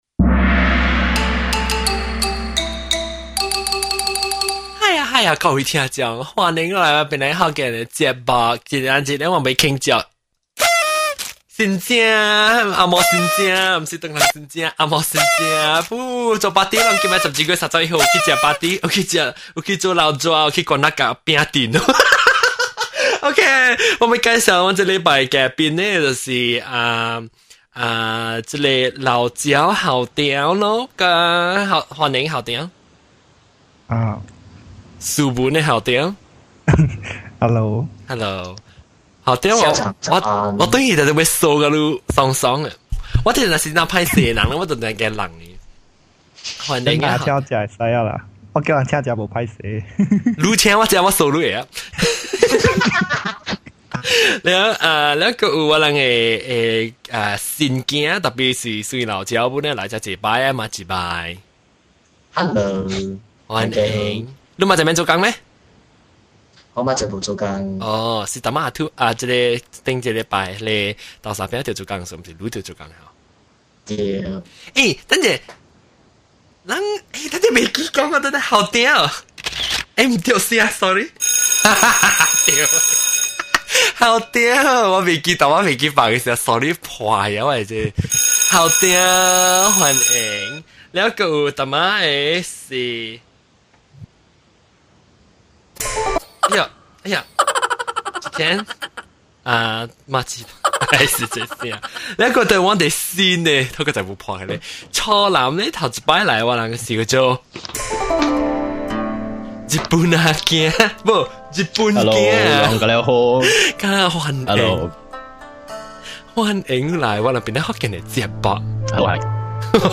Come join our chat about the new year to gym to all sorts of topics other than 2008.